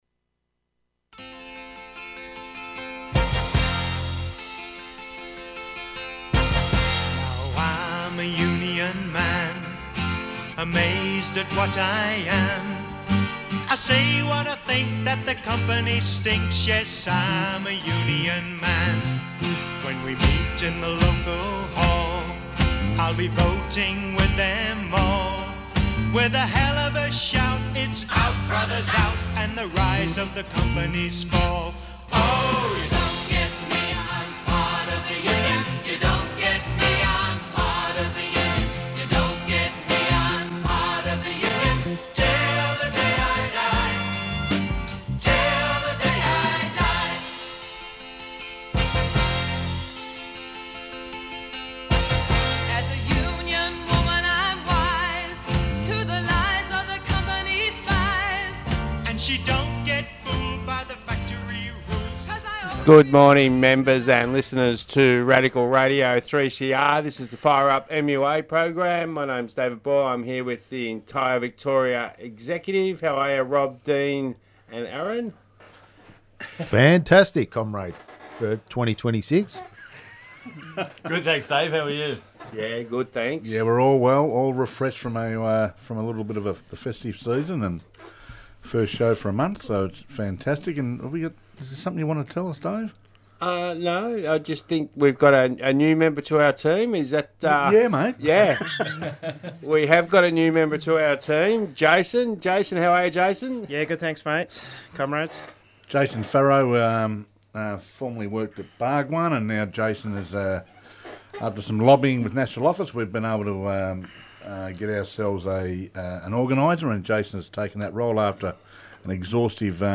First show back for 2026, presented by the MUA (Vic) exec, now a team of five.